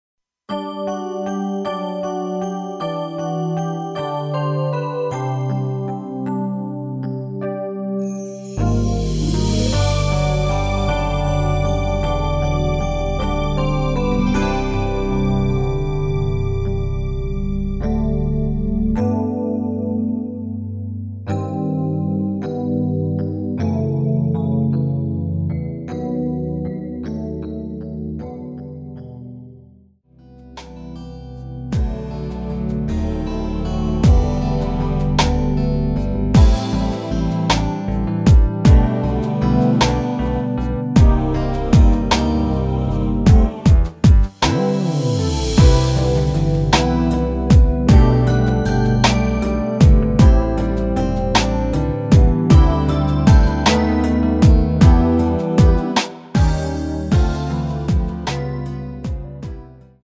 ◈ 곡명 옆 (-1)은 반음 내림, (+1)은 반음 올림 입니다.
앞부분30초, 뒷부분30초씩 편집해서 올려 드리고 있습니다.
중간에 음이 끈어지고 다시 나오는 이유는
축가 MR